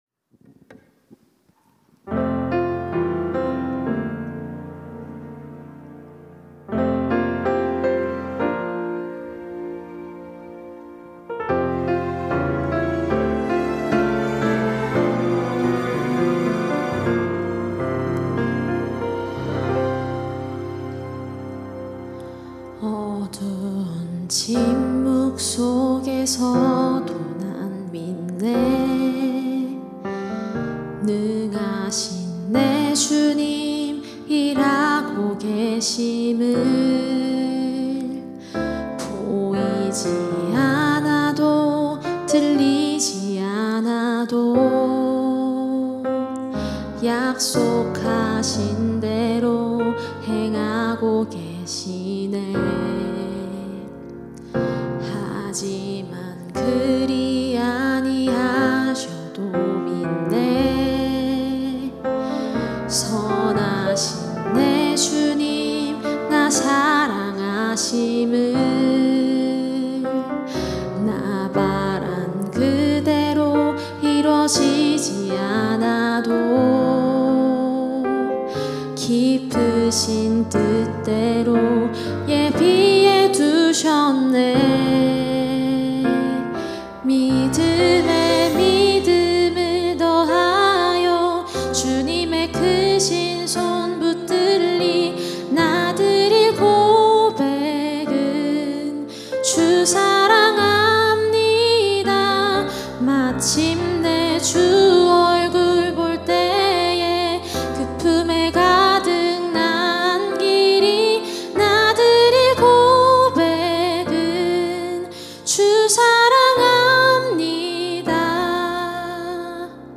특송과 특주 - 믿음에 믿음을 더하여